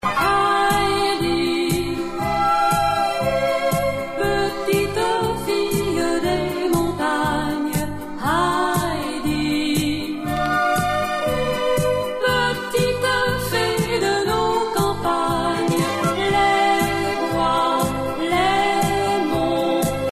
(à la tyrolienne)